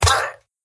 Minecraft Villager Death